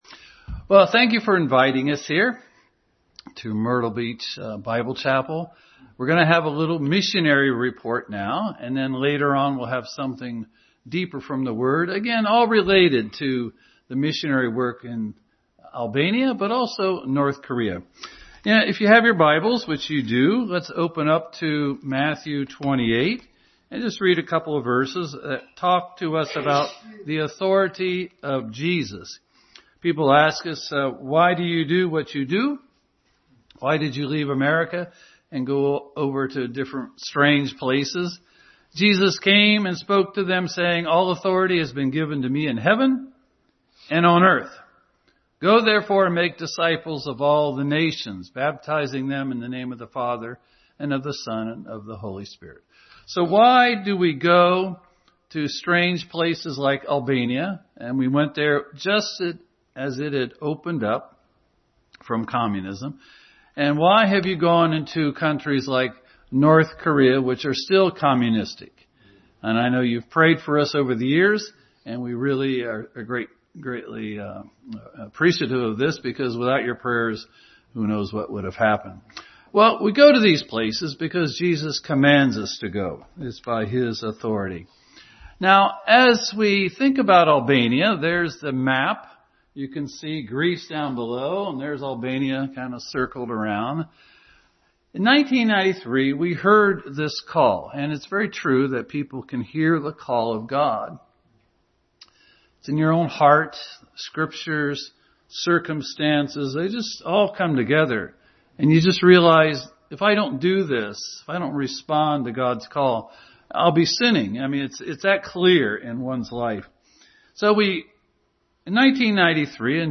Missionary Report Passage: Matthew 28:18, Romans 15:19, 8:28, Isaiah 54:2 Service Type: Sunday School